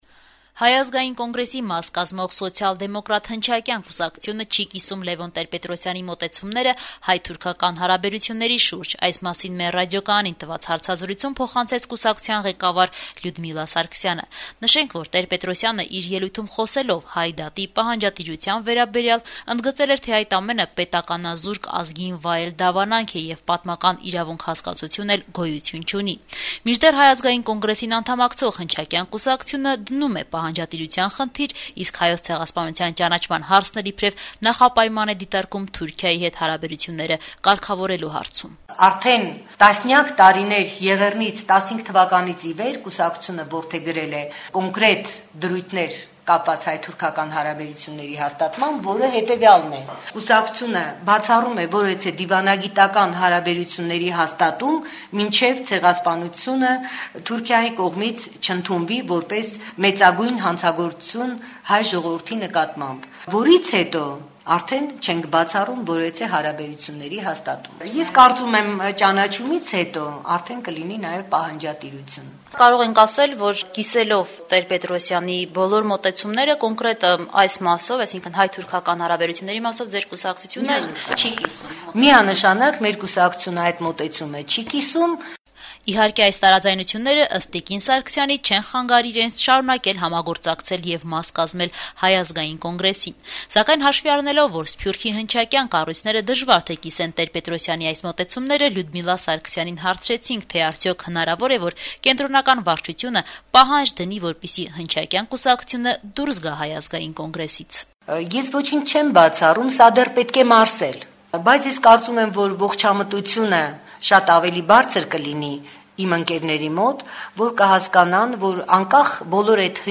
«Ազատություն» ռադիոկայանի հետ հարցազրույցում